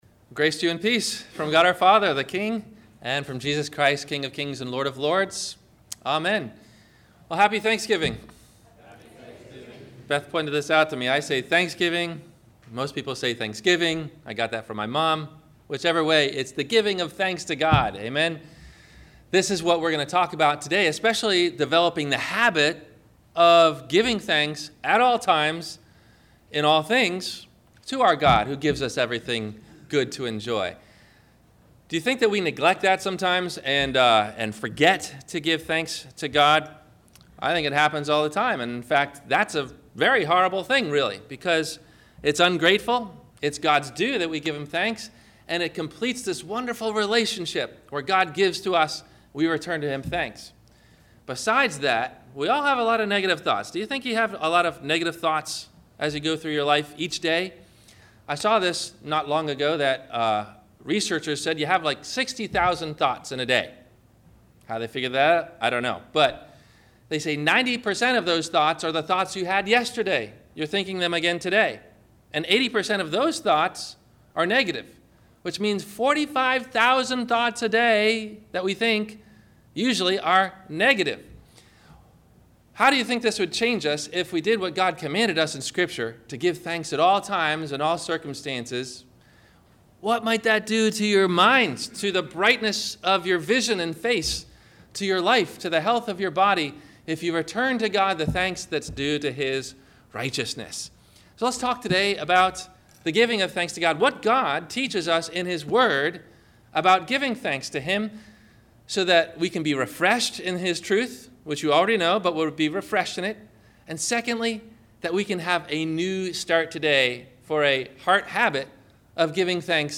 The Greatest Words we can say to God : Thank You – Thanksgiving Day Sermon – November 24 2016